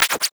scratch_004.ogg